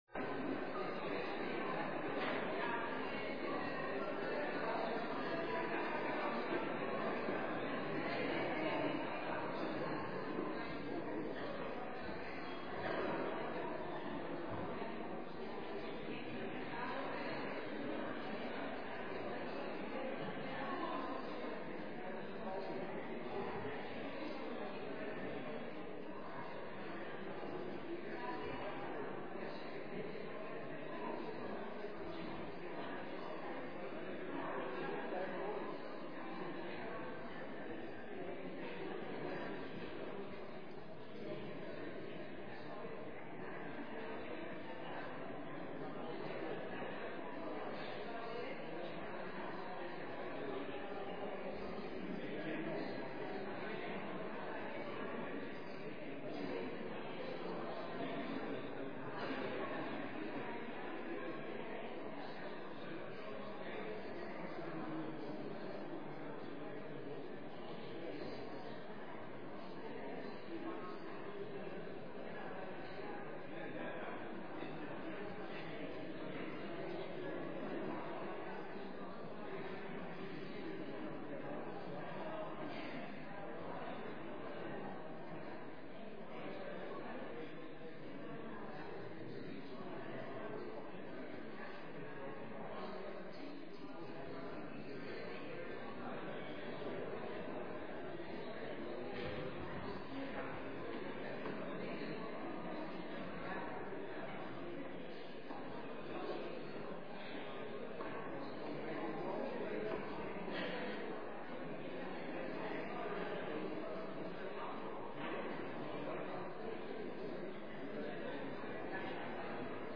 Op zondag 22 november was het de laatste zondag van het kerkelijk jaar en hebben wij onze overleden gemeenteleden herdacht. In de morgenvieringen zijn hun namen genoemd in het licht van Gods liefde.